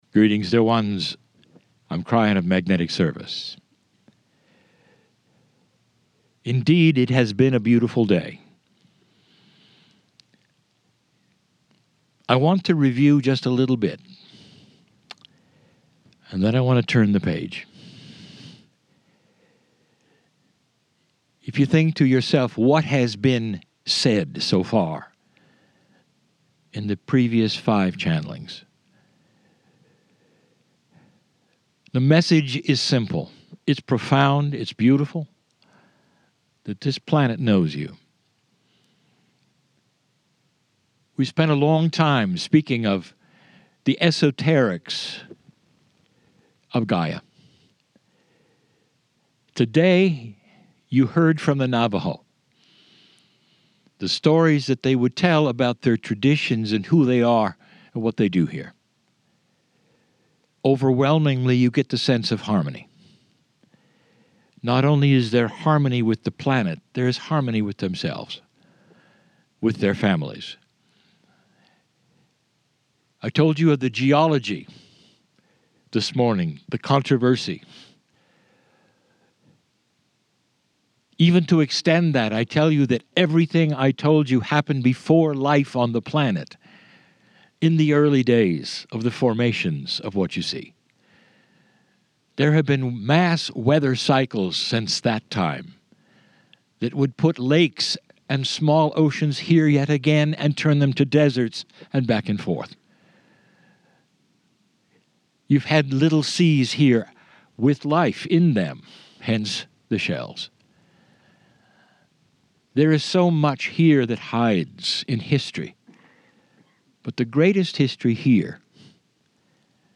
The Kryon Monument Valley Tour, April, 2017
KRYON CHANNELLING